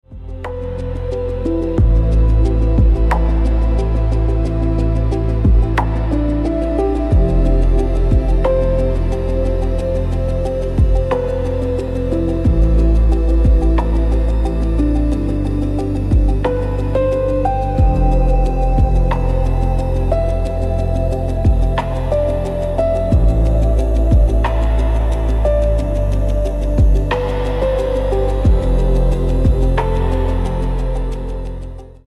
Ambient
Minimal atmospheric sound for subtle emotional background